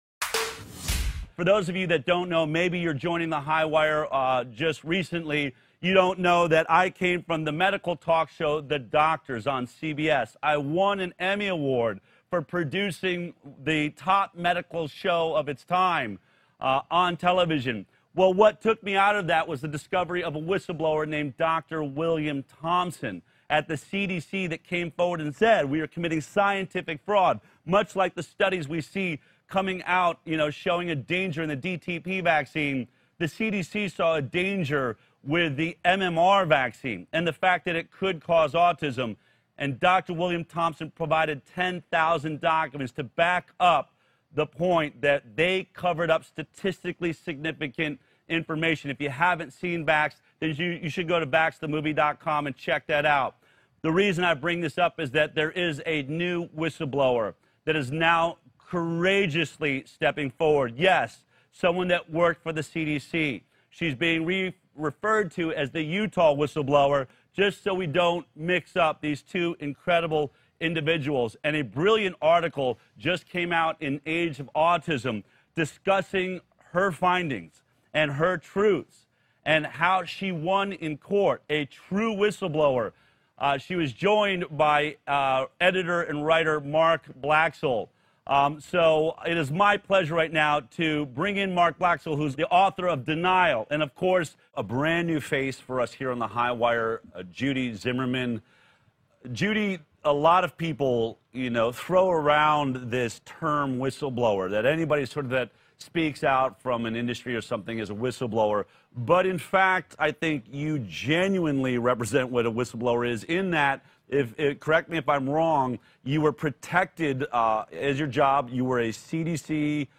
Tuore USA:n tarttuvien tautien viraston ilmiantaja haastattelussa